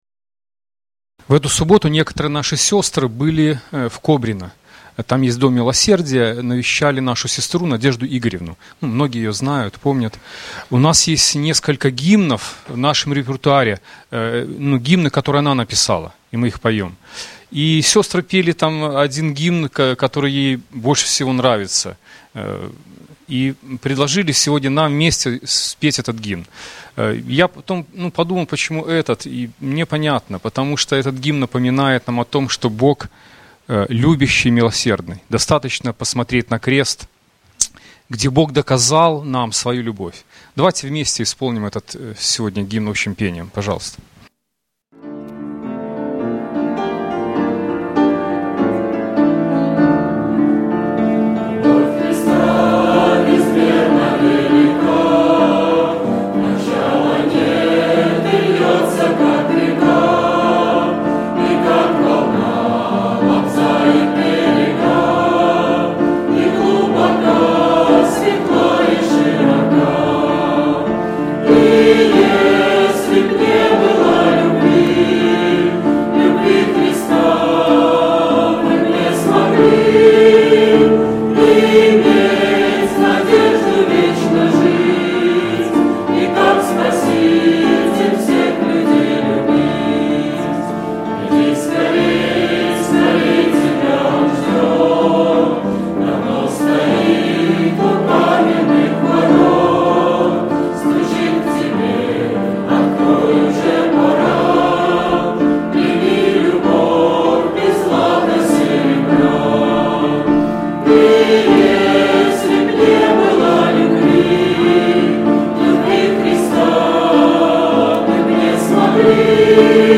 24-05-15 / Общее пение